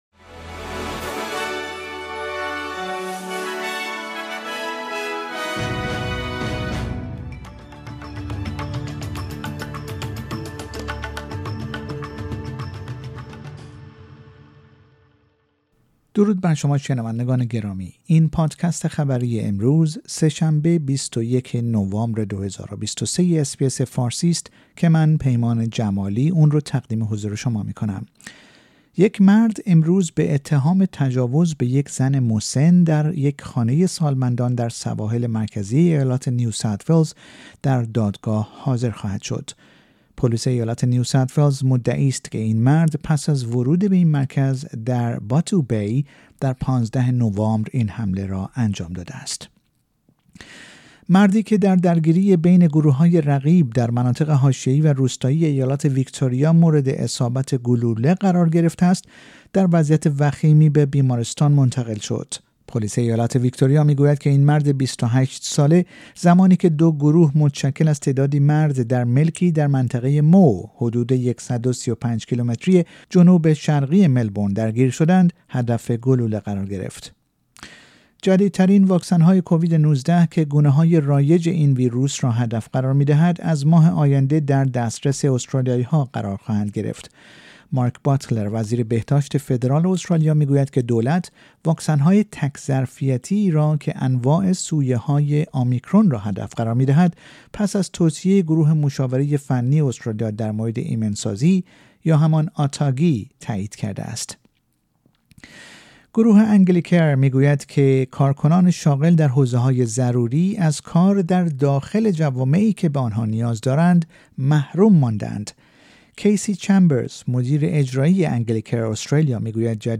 در این پادکست خبری مهمترین اخبار استرالیا و جهان در روز سه شنبه ۲۱ نوامبر ۲۰۲۳ ارائه شده است.